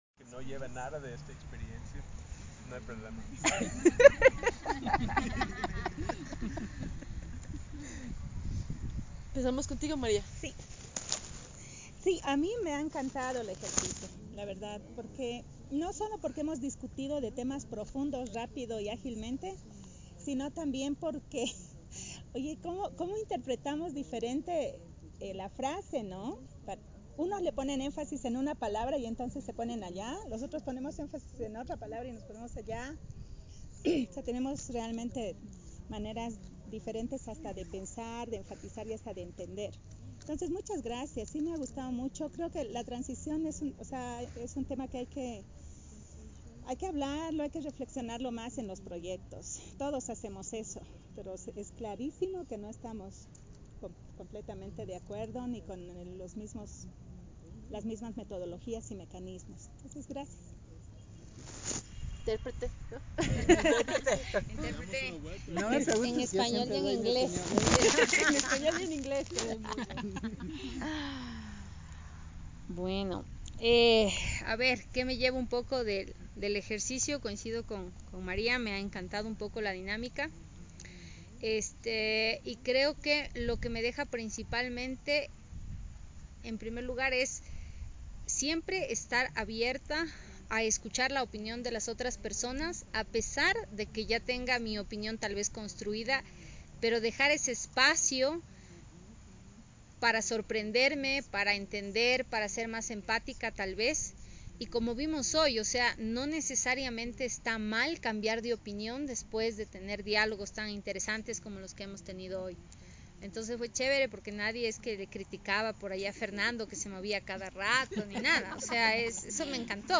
Descargar Escuchar la Presentación Sin Acción Colectiva 0:00